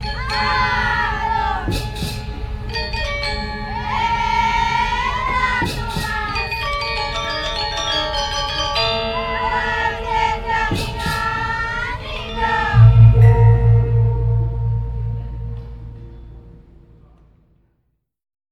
Alle Sounds gibt es in 2 Formaten, im RealAudio-Format in ausreichender Qualität und im MP3-Format in sehr guter Qualität.
2 Willkommensound lang